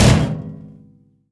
metal_drum_impact_thud_08.wav